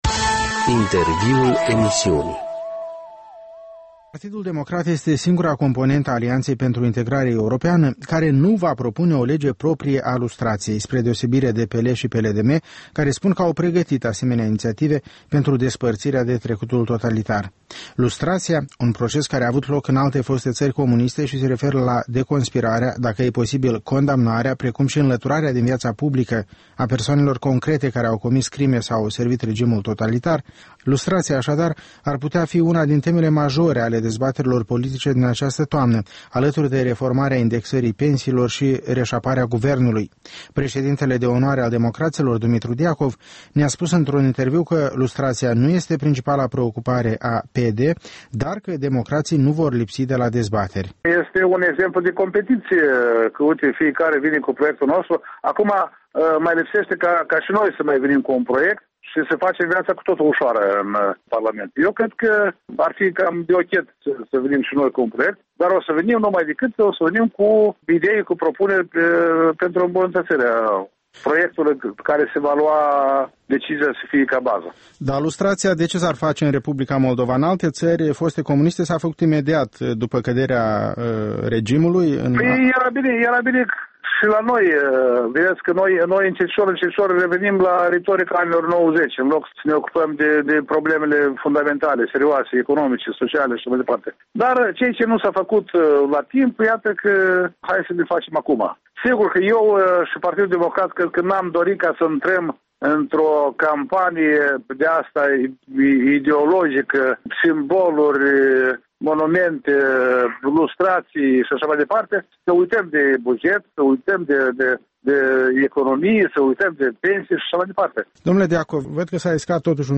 Dumitru Diacov despre Partidul Democrat și intențiile sale în dialog